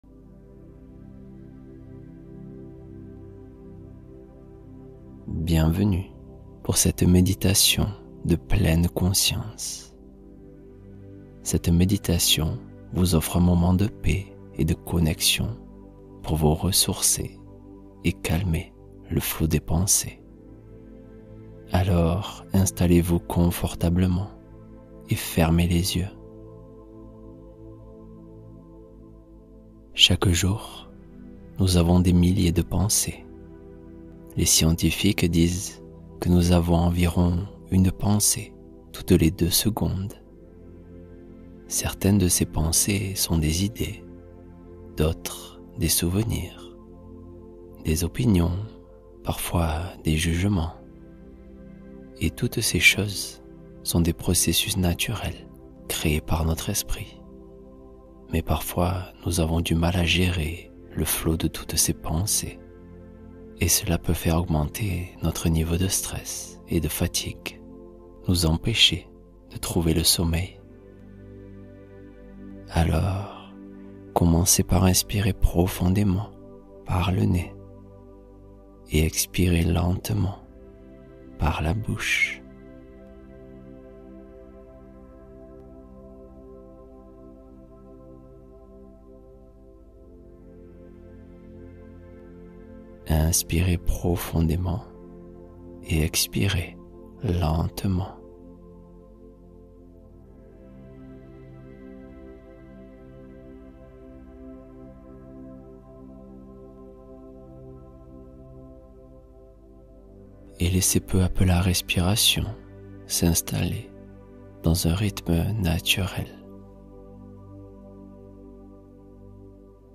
Histoire du soir : immersion pour un sommeil profond